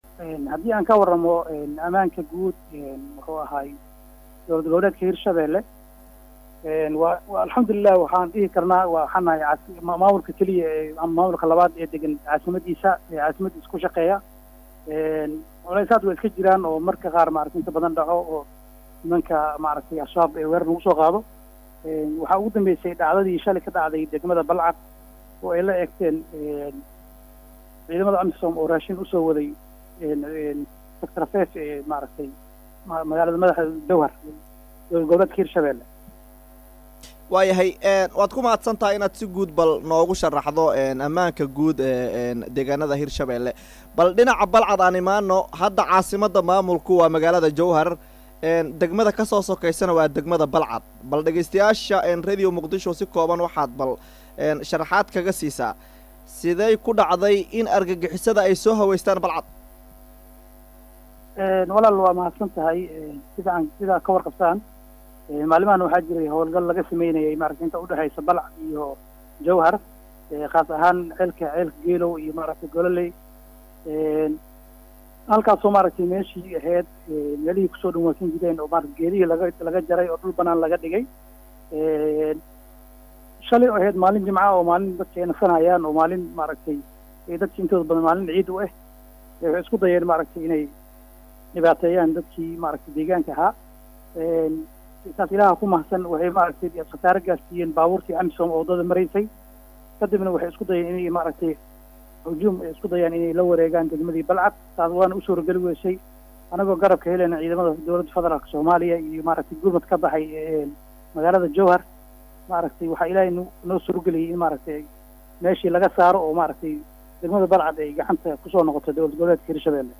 Wasiirka Amniga Maamulka Hir-shabeele C/qaadir aadan Jeele oo la hadlay Radio Muqdisho Codka Jmahuuriyadda Soomaaliya ayaa faah faahin ka bixiyay